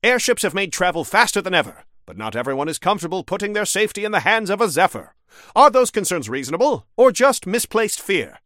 Newscaster_headline_68.mp3